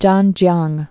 (jänjyäng)